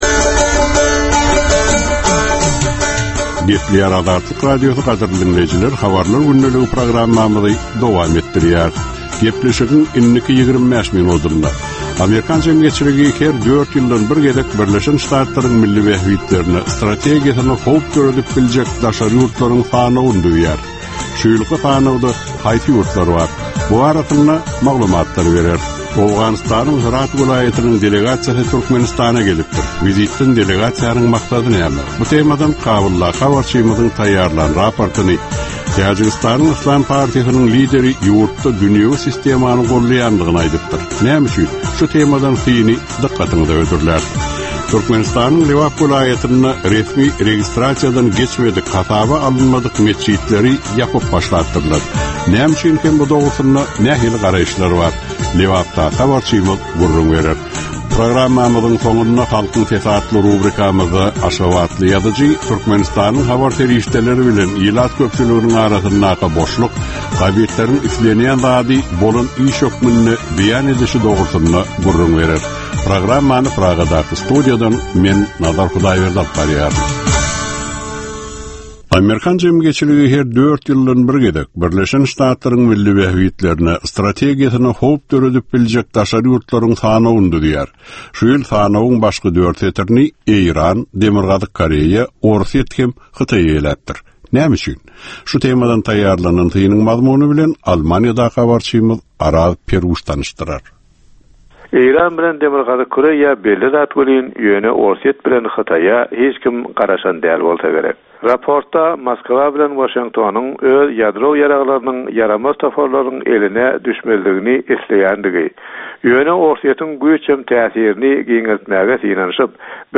Türkmenistandaky we halkara arenasyndaky soňky möhüm wakalar we meseleler barada ýörite informasion-analitiki programma. Bu programmada soňky möhüm wakalar we meseleler barada giňişleýin maglumatlar, analizler, synlar, makalalar, söhbetdeşlikler, reportažlar, kommentariýalar we diskussiýalar berilýär.